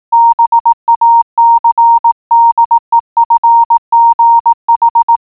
Morse.wav